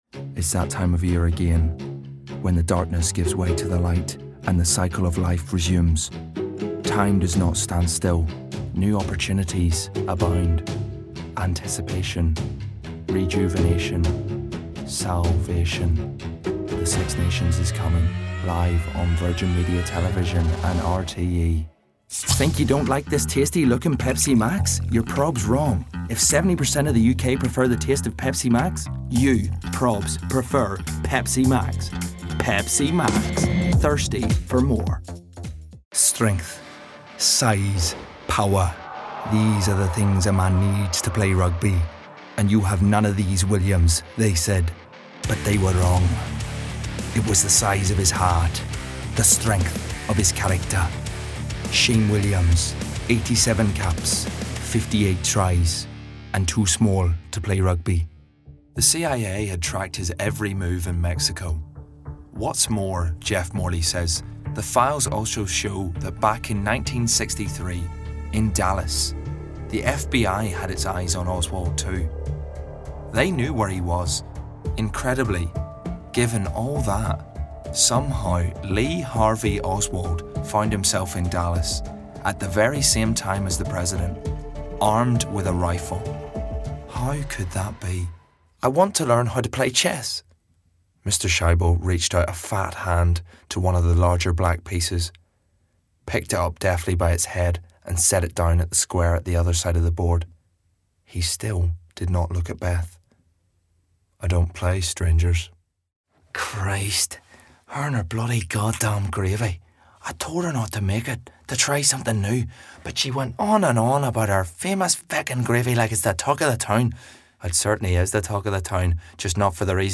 Northern Irish
Voicereel: